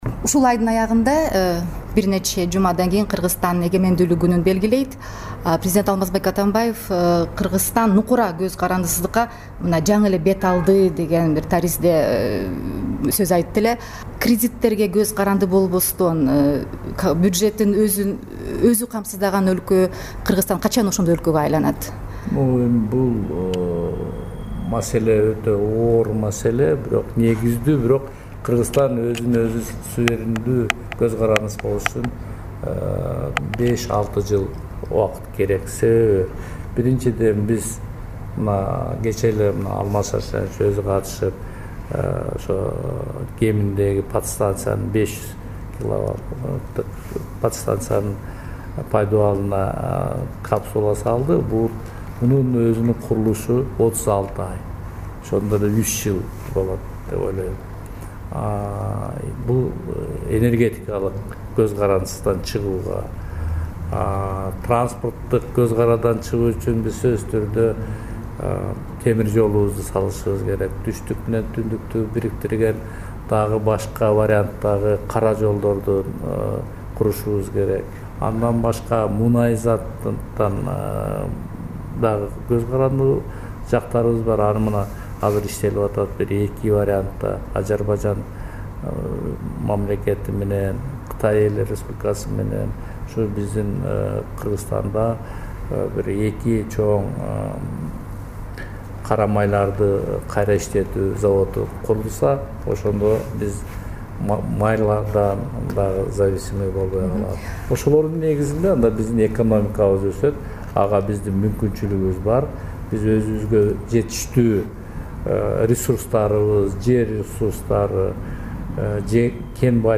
Жантөрө Сатыбалдиев менен маек